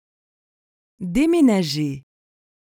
The pronunciation of déménager is: [de-me-na-ʒe].